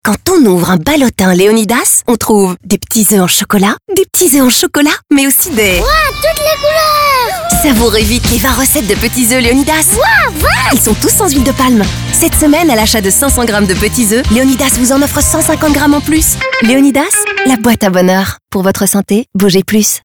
La franchise Leonidas lance un nouveau sport radio pour Pâques !